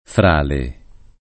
frale [ fr # le ]